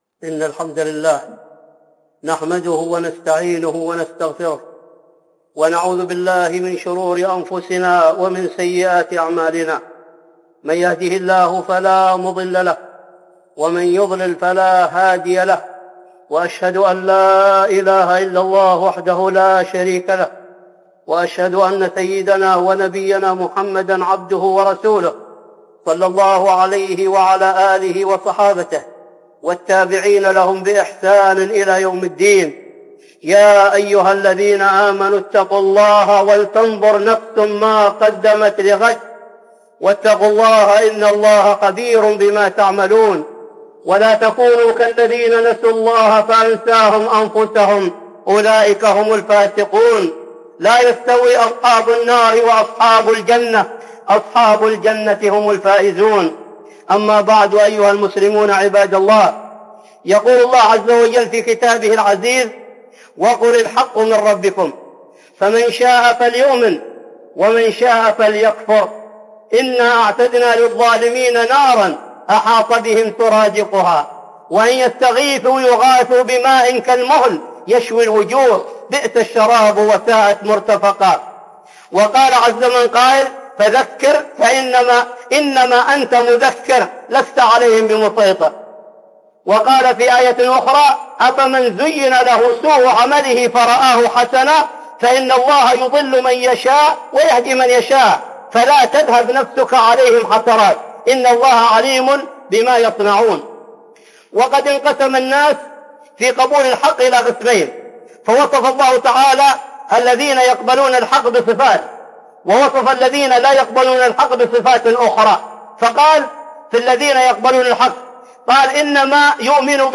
(خطبة جمعة) أسباب الوقوع في المظاهرات